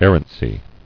[er·ran·cy]